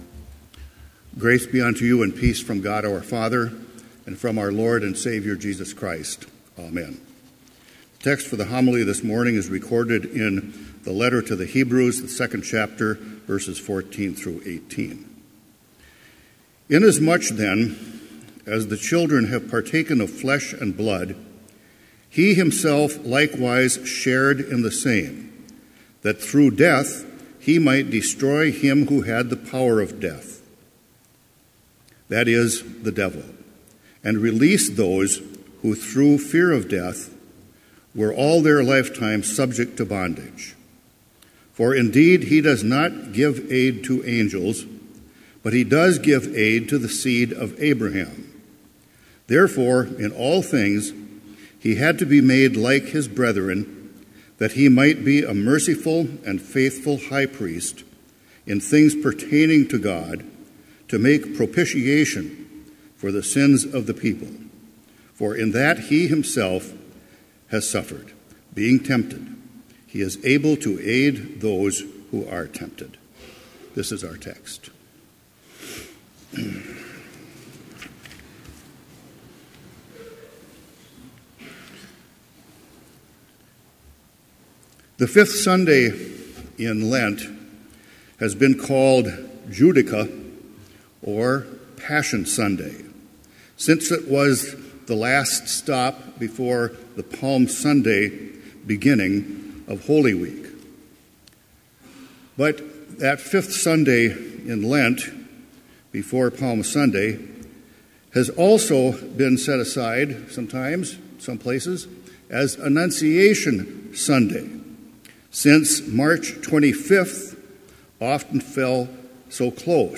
Complete Service
• Prelude
• Hymn 276, vv. 1 – 3, When O'er My Sins I Sorrow
• Postlude
This Chapel Service was held in Trinity Chapel at Bethany Lutheran College on Wednesday, April 5, 2017, at 10 a.m. Page and hymn numbers are from the Evangelical Lutheran Hymnary.